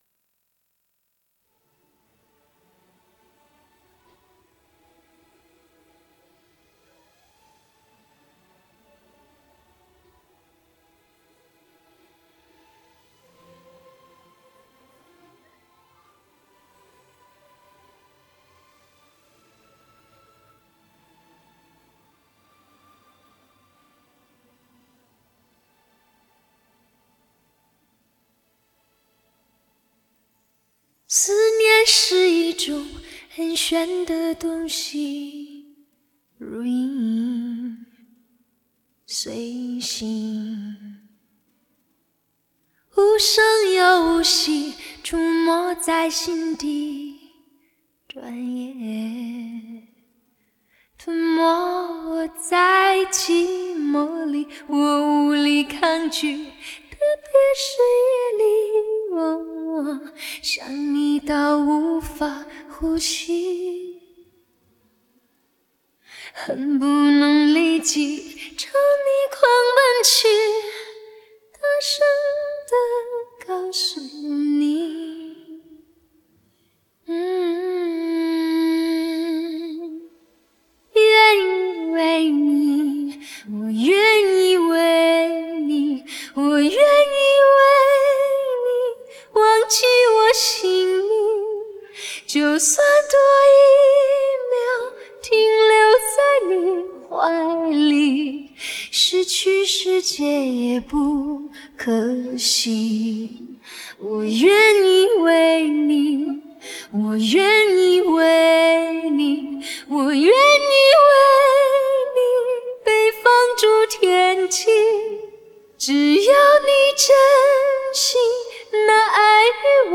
Rename 3_3_(Vocals).wav to 3.wav